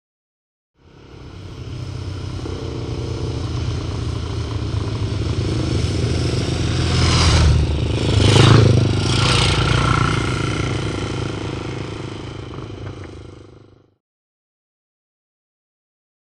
Motorcycle; By; Triumph Twin And Two B.s.a. Singles Up And Past Mic.